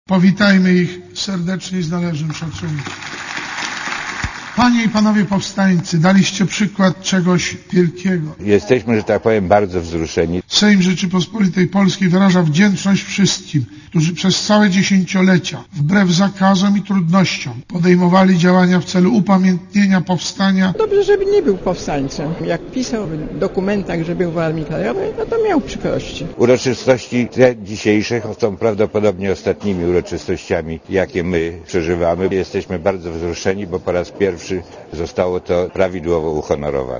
Komentarz audio Sejm ogłosił 2004 rok, Rokiem Pamięci Powstania Warszawskiego .